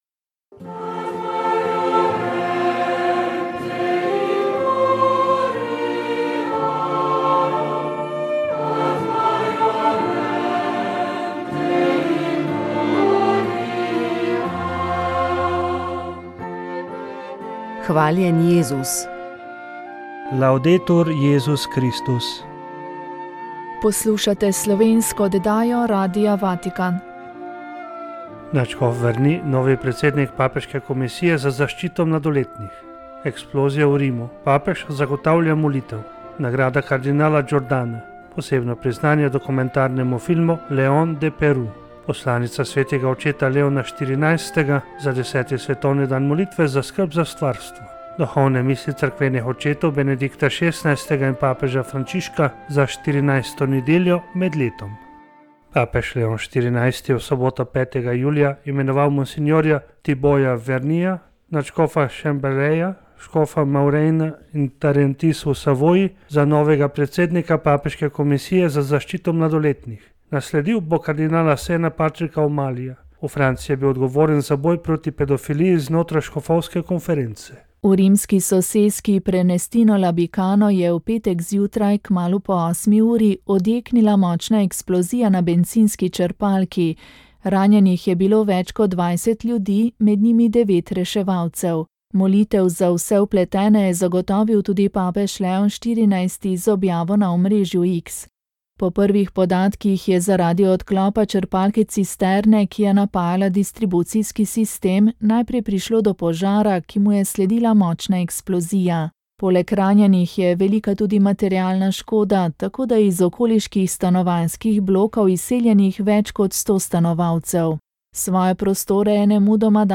V oddaji Moja zgodba ste lahko prisluhnili drugemu delu spominov nekdanjega politika, poslanca, ekonomista Izidorja Rejca. V prvem delu pričevanja je spregovoril o svojem življenju v socializmu, tokrat pa je povedal, kako je bil leta 1989 med ustanovitelji Slovenskega krščansko-socialnega gibanja in nato med vodilnimi pri Slovenskih krščanskih demokratih.